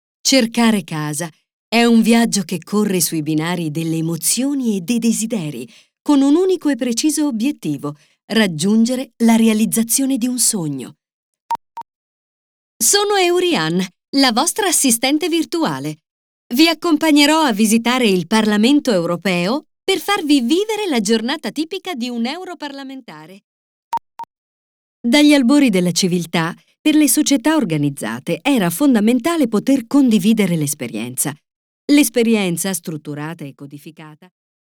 Sprechprobe: Sonstiges (Muttersprache):
Very adaptable voice, young, warm, pleasant, sexy, professional...